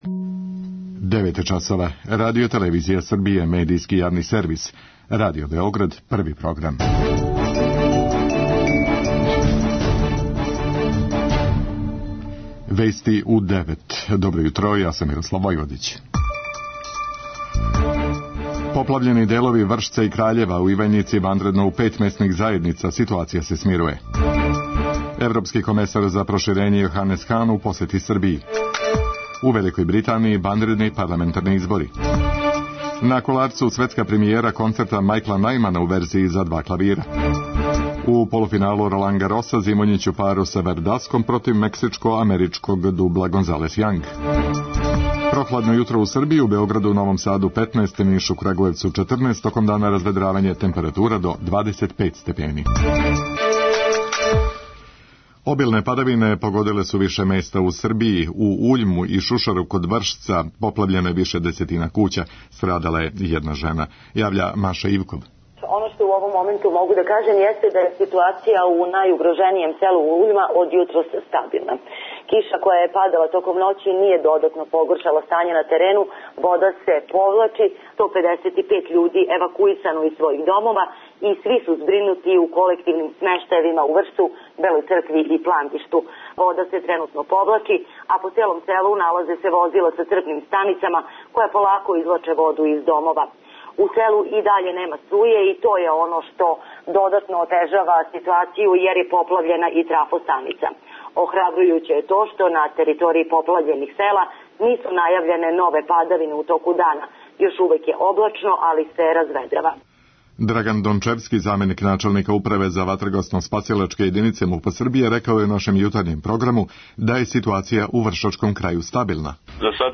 преузми : 3.48 MB Вести у 9 Autor: разни аутори Преглед најважнијиx информација из земље из света.